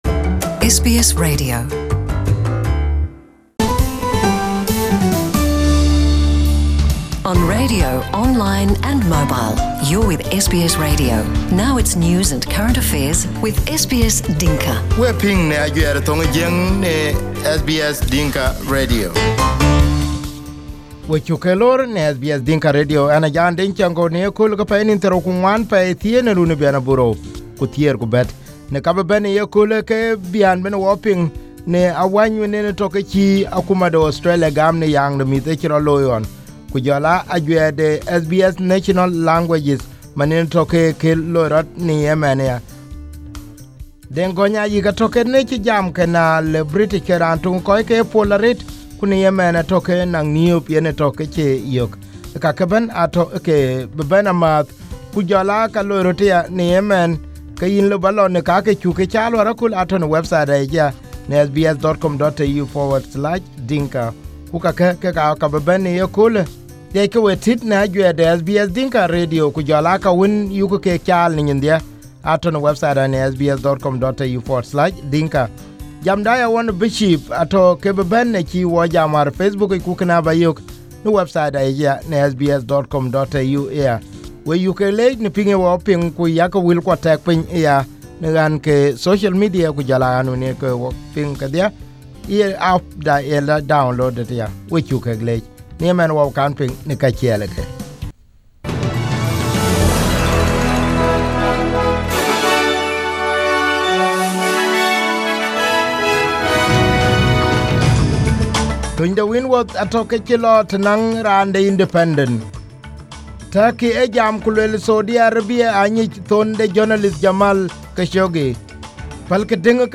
SBS Dinka News Bulletins